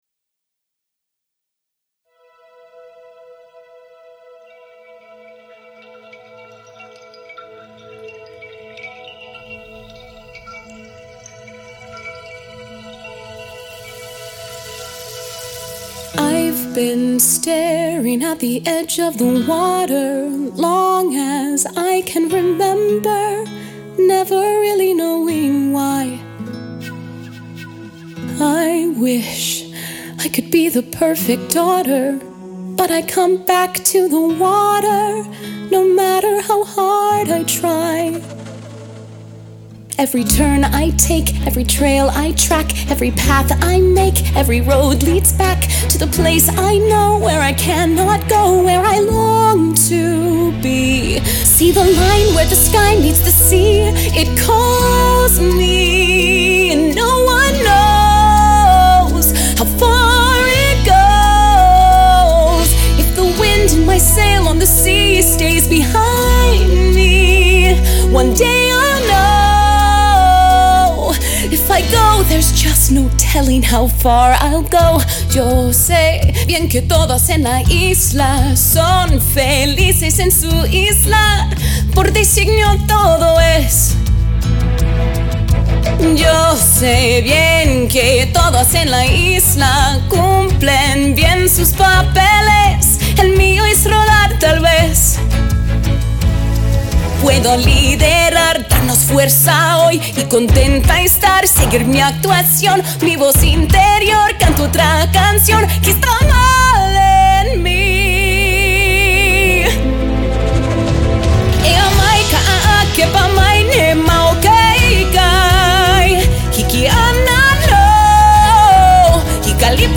(English/Spanish/Hawaiian version)